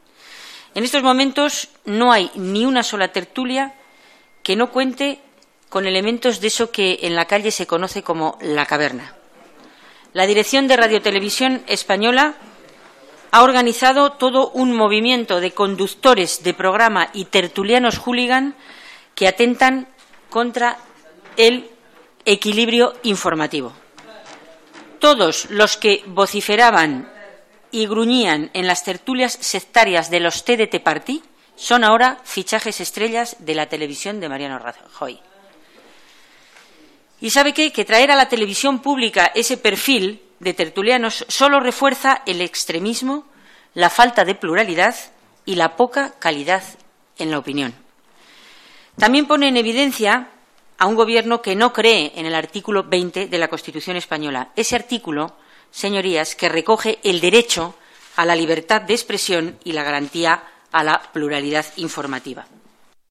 Fragmento de la intervención de Angeles Alvarez en la Comisión de Control de RTVE. Pregunta al presidente de RTVE: ¿Cuáles son los criterios para la elección de los tertulianos que participan en programas de RTVE? 16/12/2014